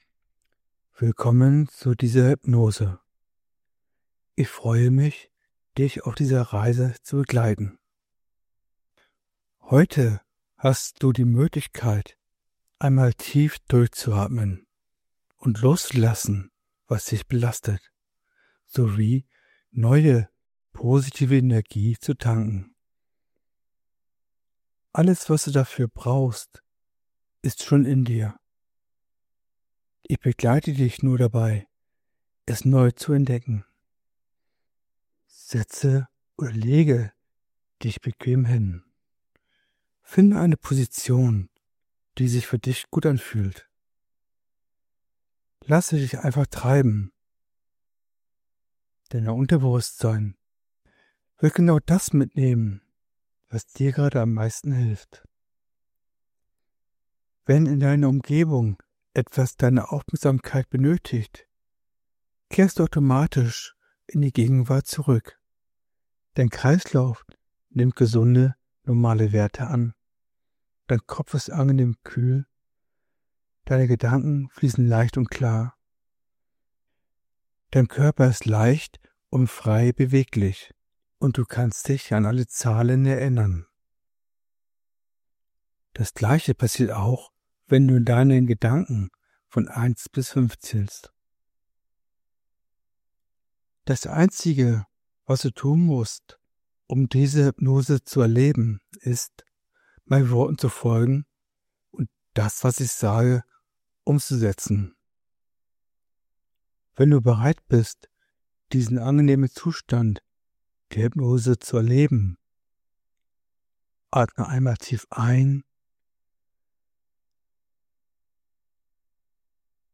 uem-hypnose.mp3